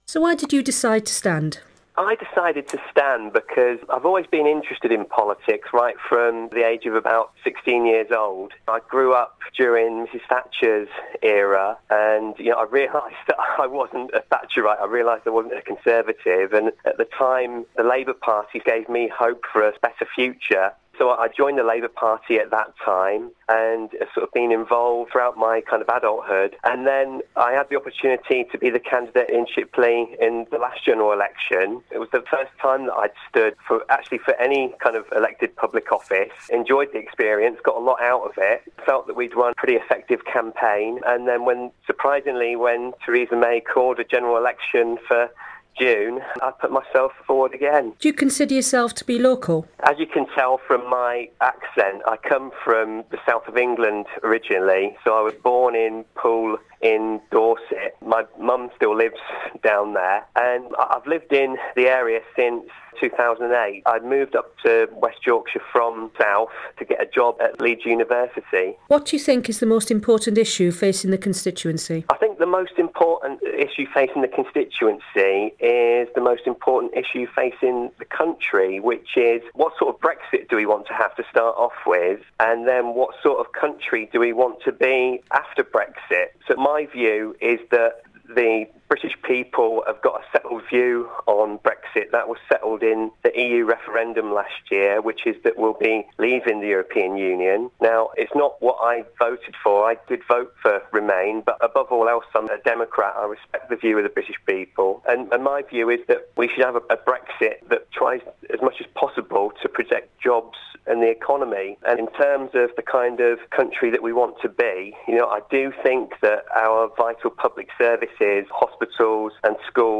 In the buildup to the general election, we're interviewing all the parliamentary candidates standing in Bradford's five constituencies about their policies and pledges.